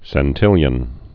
(sĕn-tĭlyən)